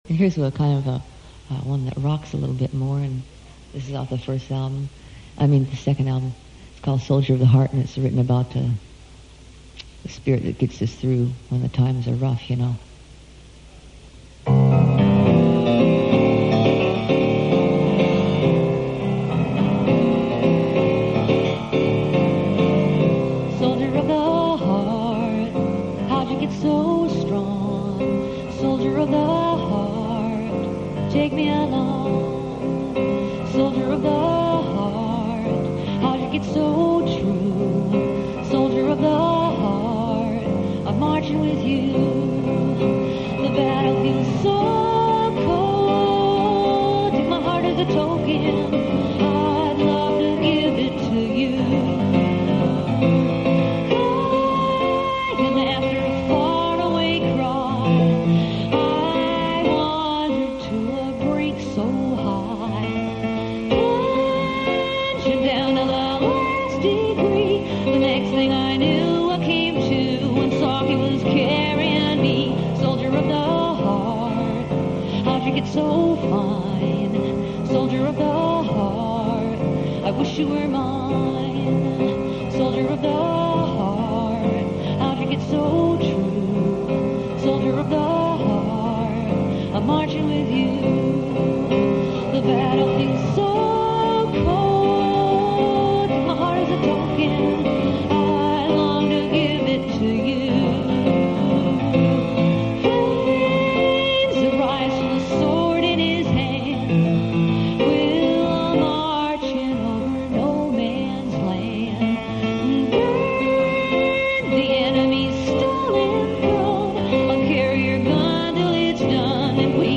The audio quality of these ranges from poor to very good.